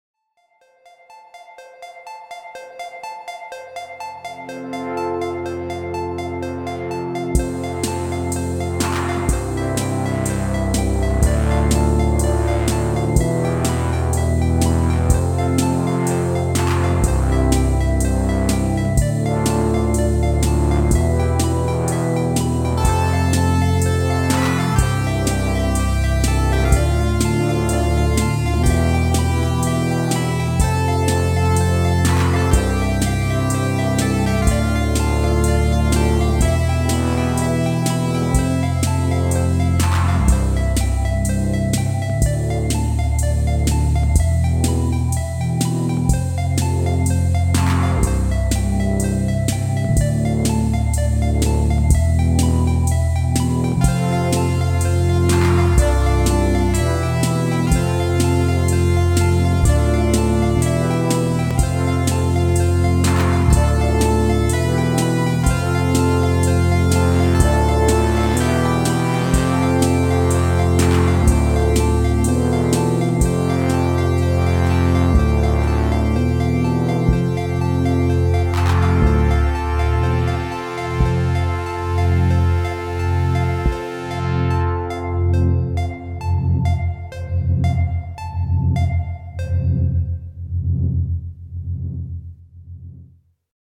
Layering up sounds from the Volca Keys